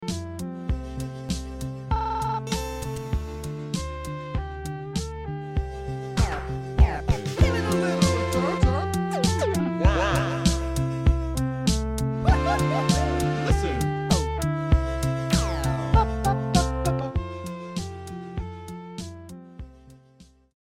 extended intro made by me
micropop